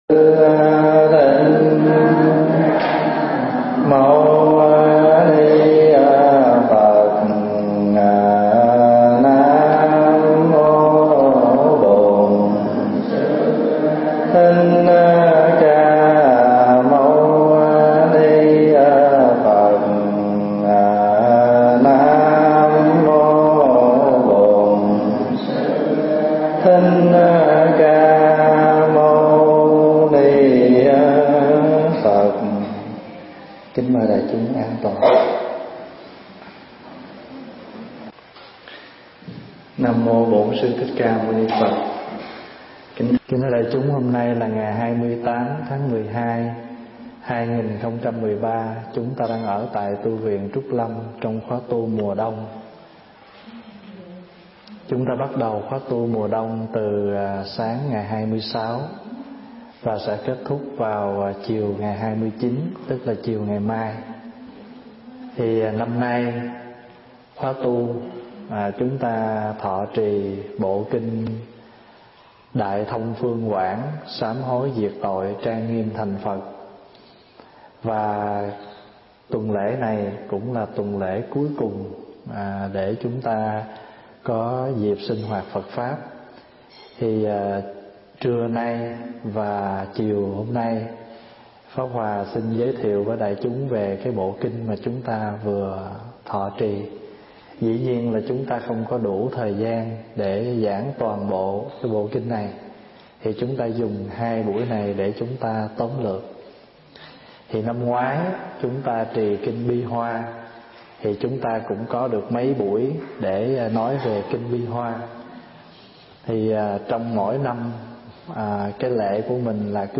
thuyết giảng tại Tu Viện Trúc Lâm, Canada, ngày 28 tháng 12 năm 2013